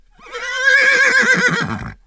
Neigh1.wav